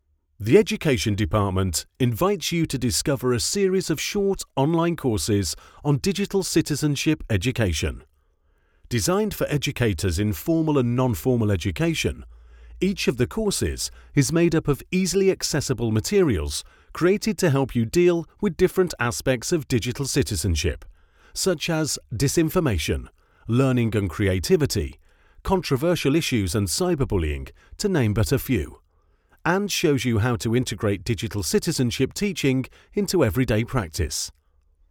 Inglés (Británico)
Profundo, Seguro, Maduro, Amable, Cálida
E-learning